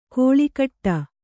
♪ kōḷikaṭṭa